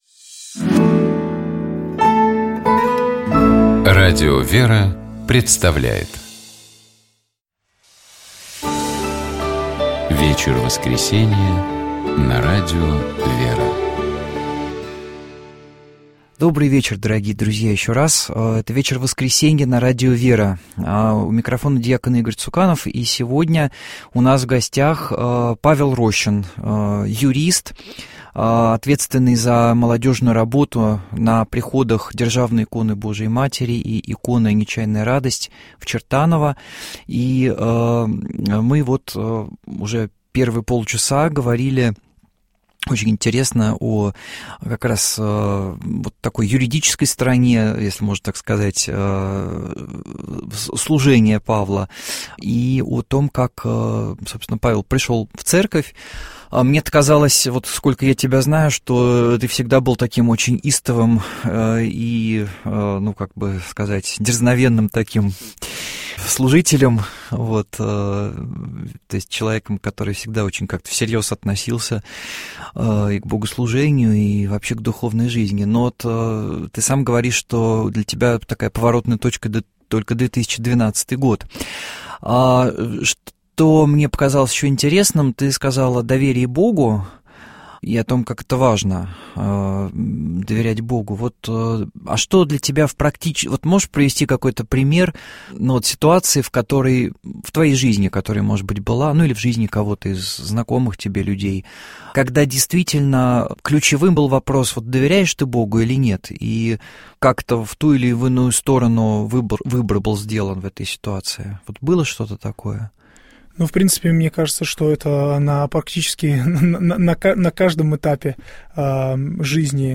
У нас в гостях был юрист